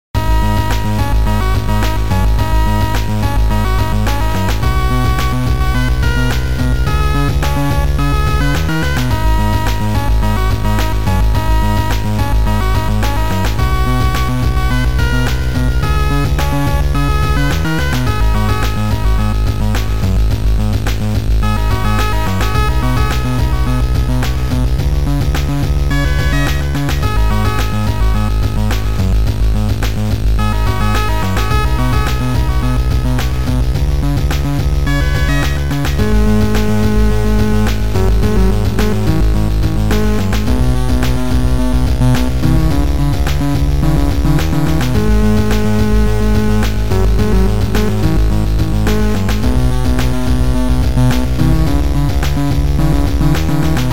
Sound Format: Noisetracker/Protracker
Sound Style: Chip